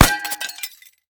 glass03hl.ogg